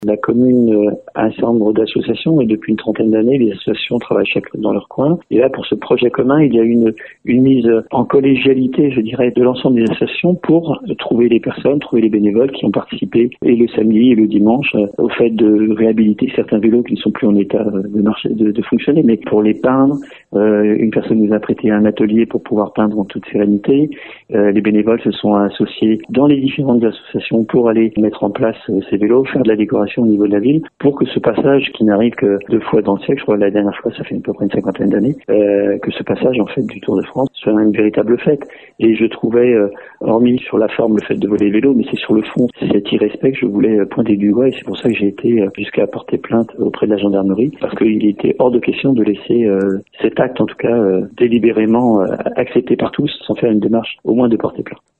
Au-delà de l’acte de vandalisme, le maire Alain Tréton dénonce un manque de respect total à l’égard du travail réalisé par les bénévoles.